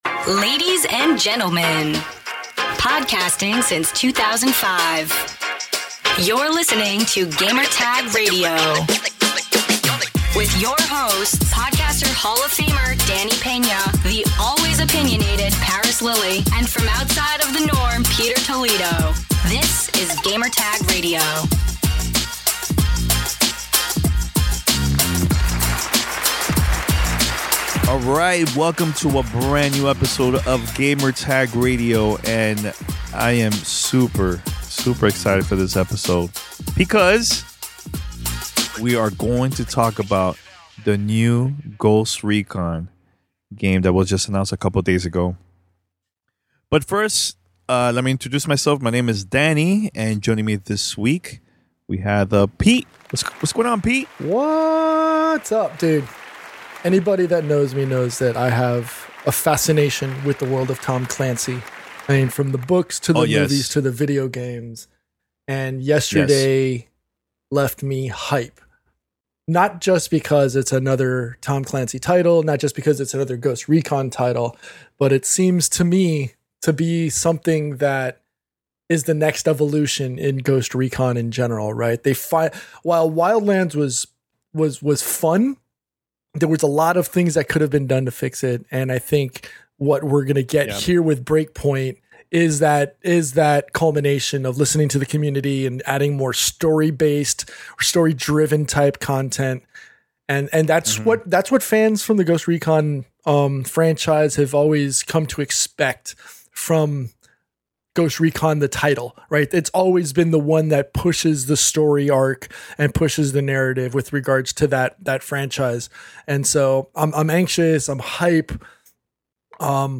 Roundtable discussion about Ghost Recon Breakpoint.